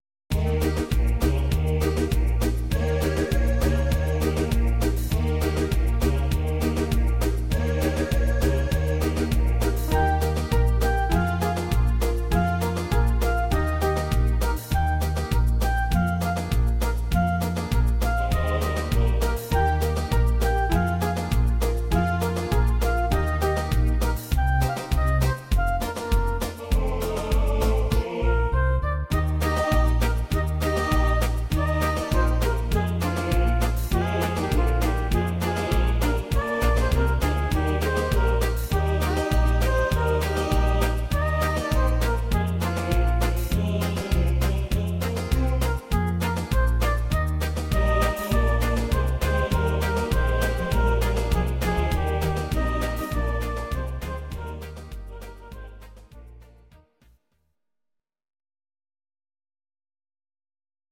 Audio Recordings based on Midi-files
German, Medleys